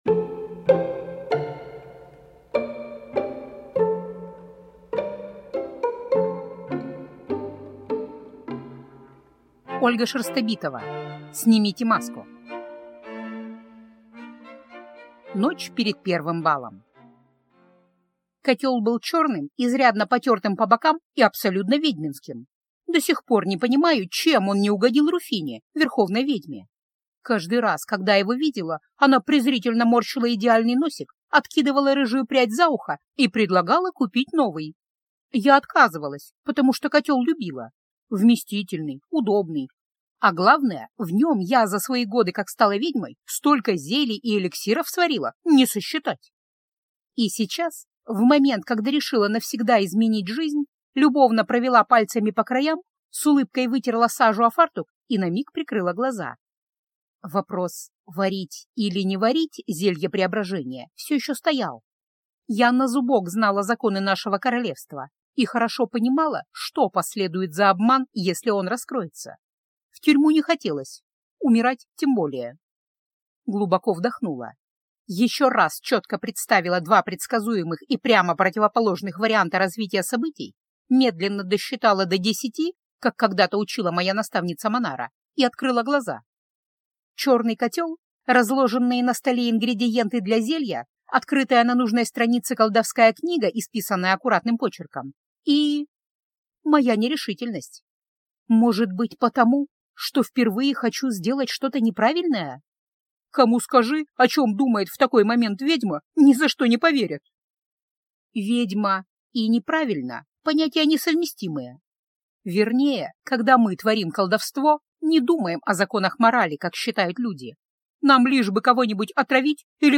Аудиокнига Снимите маску | Библиотека аудиокниг